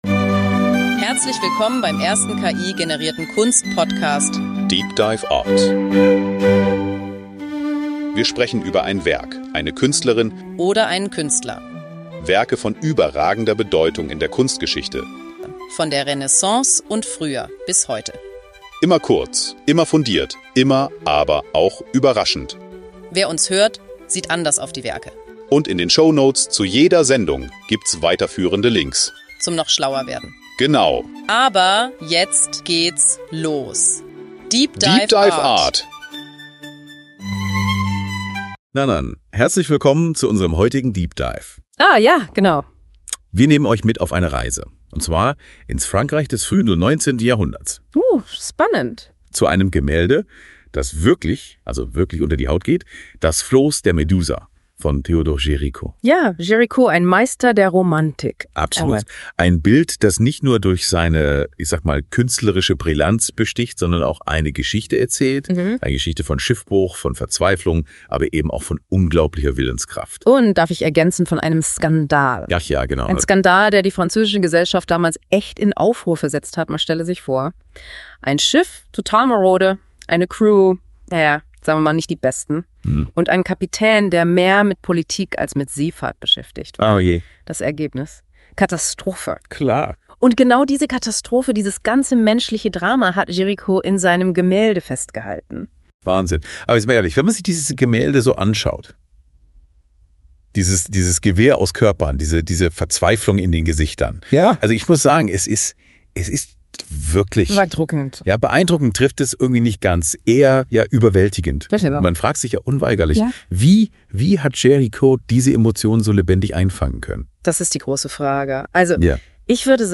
Géricaults revolutionärer Ansatz, der realistische Elemente mit romantischer Ästhetik verbindet, macht das Gemälde zu einem zeitlosen Meisterwerk, das auch heute noch relevant ist. deep dive art ist der erste voll-ki-generierte Kunst-Podcast.
Die beiden Hosts, die Musik, das Episodenfoto, alles.